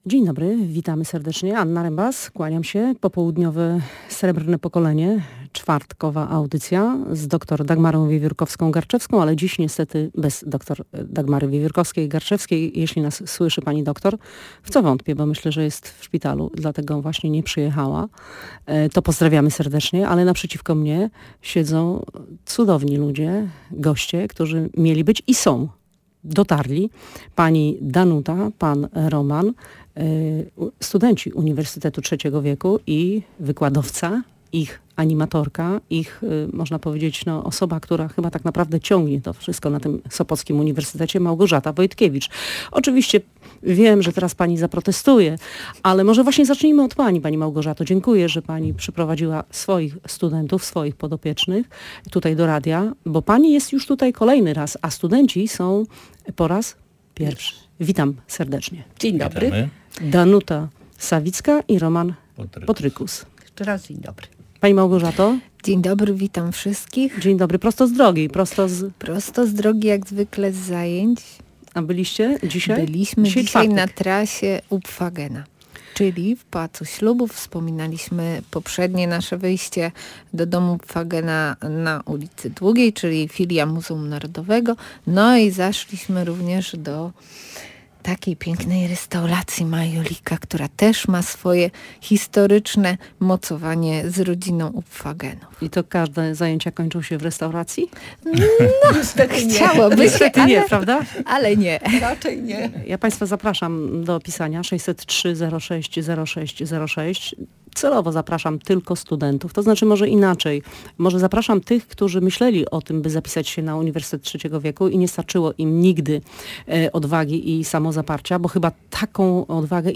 Obecni w naszym studiu rozmawiali o poszerzaniu swoich horyzontów, o tym, jakie zajęcia wybierają, jakie cieszą się największym zainteresowaniem, w ramach jakiej sekcji studiują i co daje im Uniwersytet Trzeciego Wieku. Nasi goście opowiedzieli także, czym dla seniorów jest studiowanie oraz jak wygląda integracja między ludźmi na UTW.